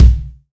INSKICK12 -L.wav